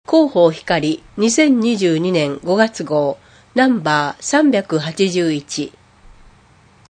こえの広報 について
広報ひかりでは、 ボランティアグループ「こだまの会」の協力により文字を読むことが困難な視覚障害者や高齢者のために広報紙の内容を音声でもお届けしています。